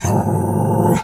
dog_2_growl_02.wav